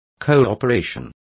Complete with pronunciation of the translation of cooperation.